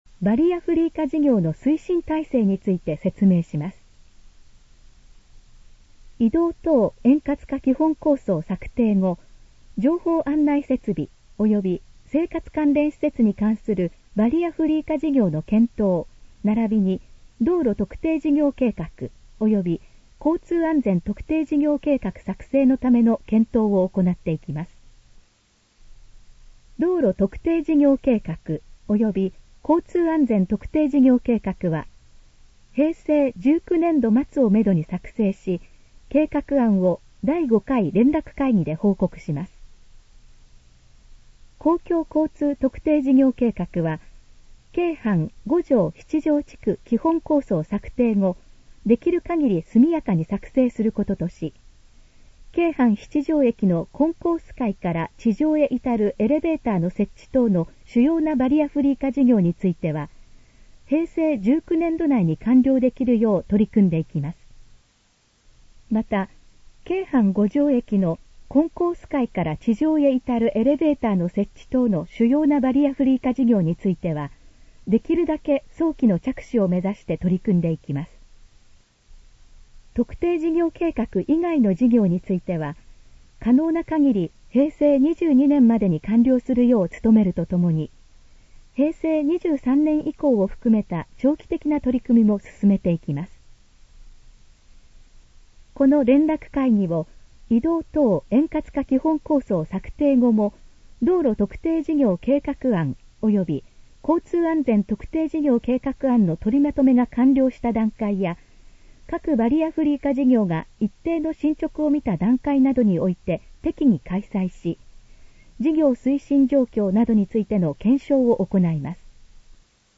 このページの要約を音声で読み上げます。
ナレーション再生 約521KB